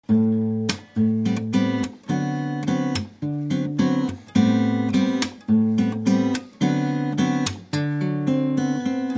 Latin Gitarre
Bossa Groove
Hier ein kleiner Bossa Rhythmus. Hier wird eine Technik namens Choke bzw. auch Ghost Notes verwendet. Dabei schlägt die rechte Hand mit den Fingerspitzen auf die Saiten, die dadurch auf die Bundstäbchen auf dem Hals (kurz vorm Schall-Loch) klatschen.
Die Basstöne spielt der rechte Daumen, die restlichen Töne schlagen Zeige-, Mittel- und Ringfinger an...